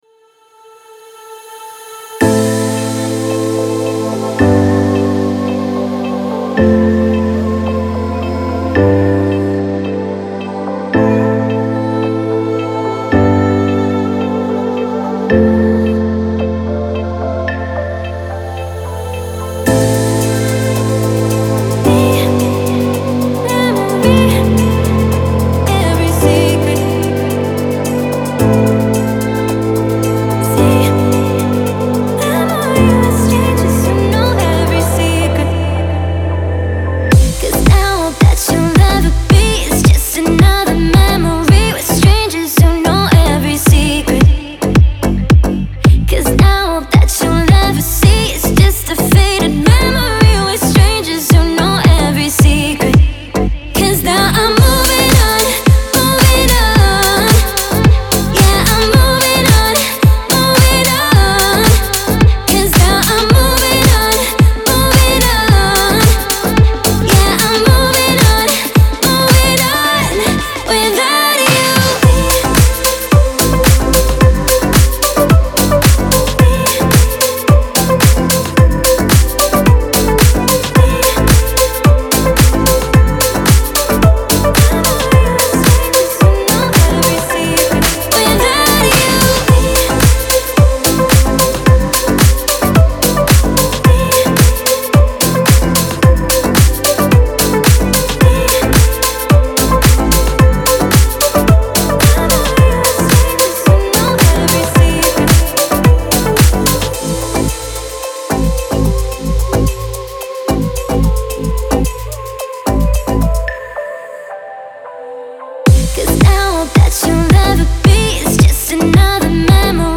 это захватывающая композиция в жанре техно